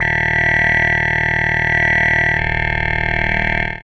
56.4 SFX.wav